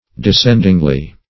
\De*scend"ing*ly\